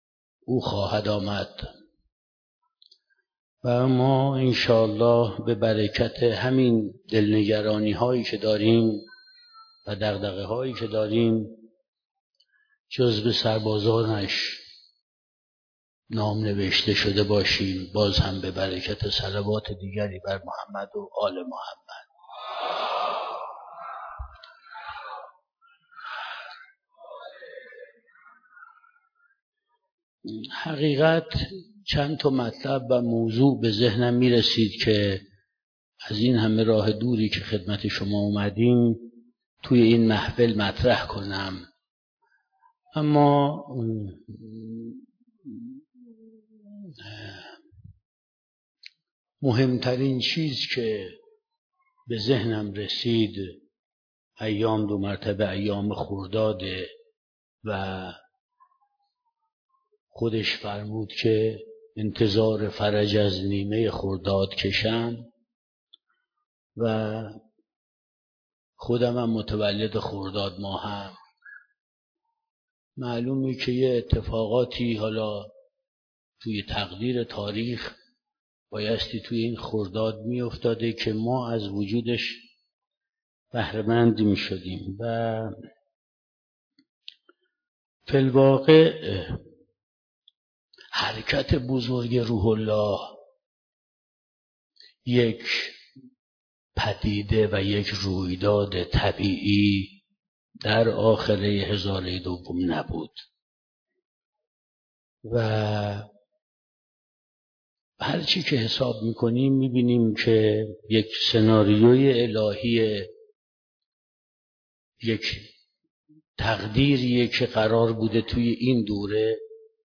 صوت / سخنرانی جنجالی و انقلابی و پرشور سردار سعید قاسمی در همایش امام، انتظار، مبارزه ؛ انتقاد جدی به دولت حسن روحانی